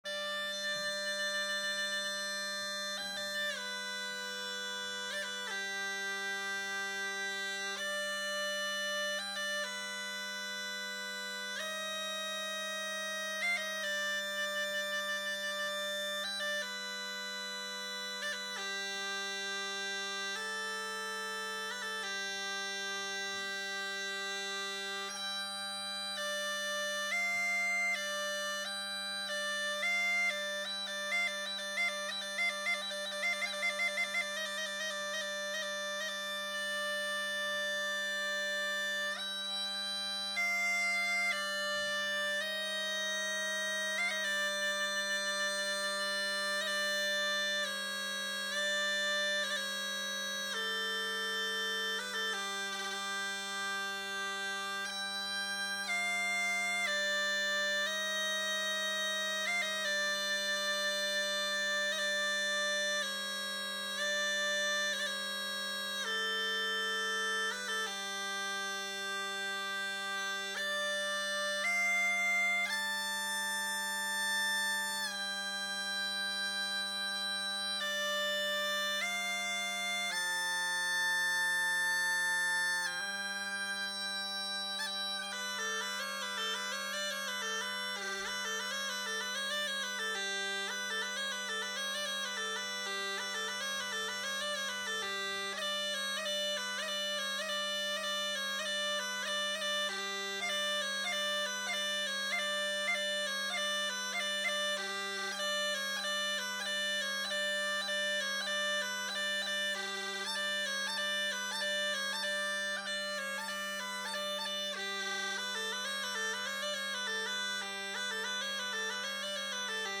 Schweizer Sackpfeifen/Swiss bagpipes
Klangbeispiel Sackpfeife mit Melodiepfeife in Naturtonreihe und Wechselbordun (freie Improvisation